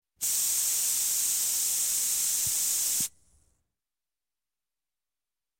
sillystring.ogg